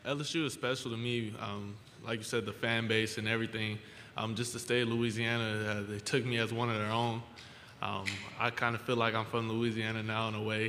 Louisiana State starting quarterback Jayden Daniels spoke Monday at the first day of SEC Media Days. He began his press conference by speaking about his transition to LSU.
Speaking confidently to the packed room, he took the opportunity to discuss how pleased he was with his decision to transfer.